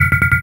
В коллекции представлены различные варианты предупреждающих сигналов, блокировок и системных оповещений.
Доступ запрещен